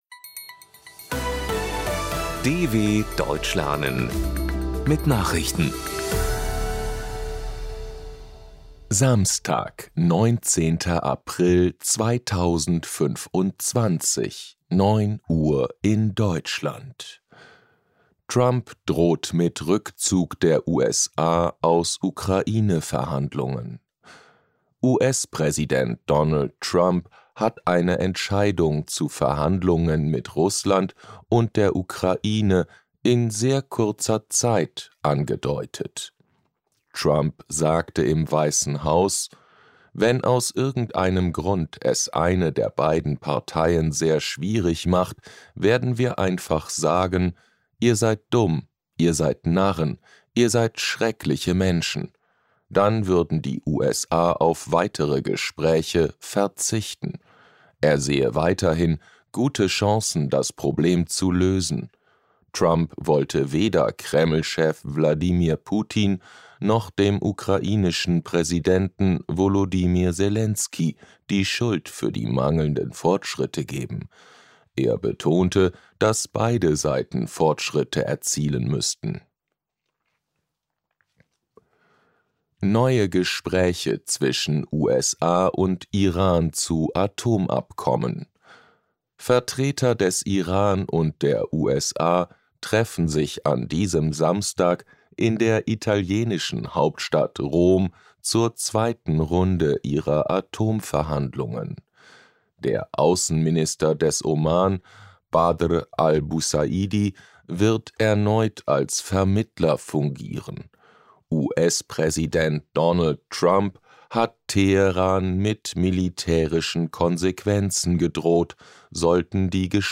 Das langsam und verständlich gesprochene Audio trainiert das Hörverstehen.